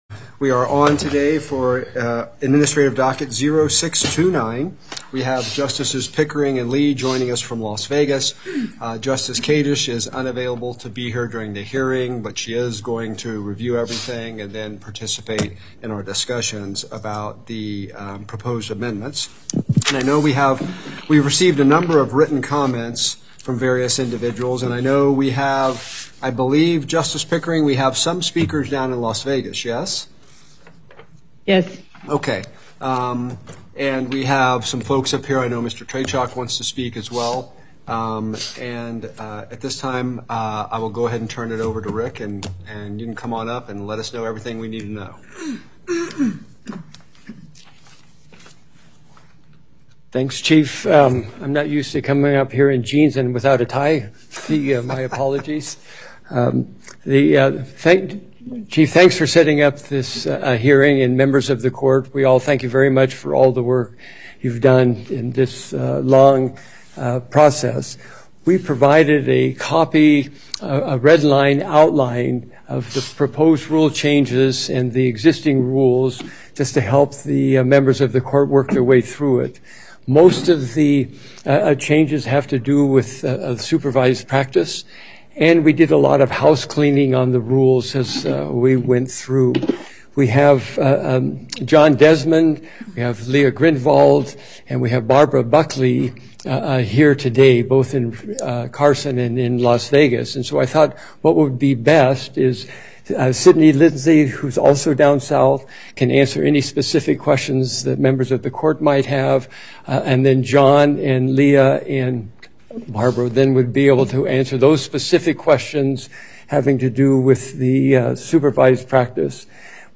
Before the En Banc Court, Chief Justice Herndon presiding
Public comment